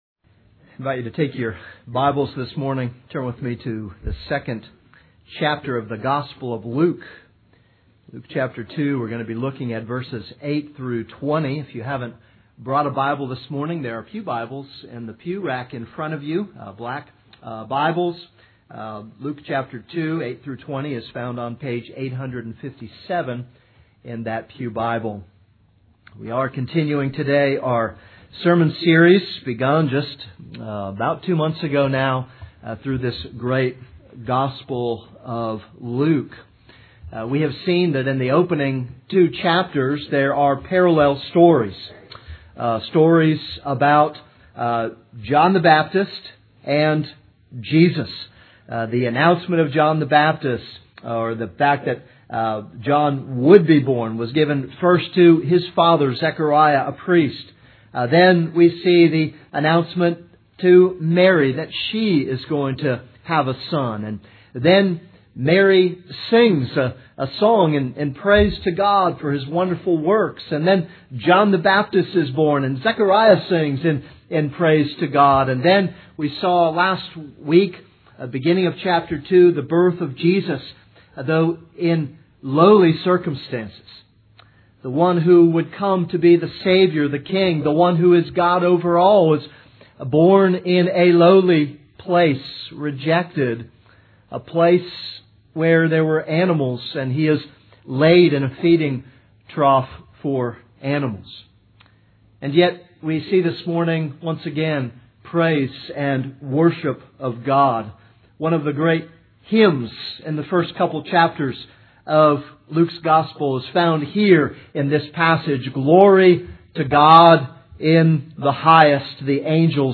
This is a sermon on Luke 2:8-20.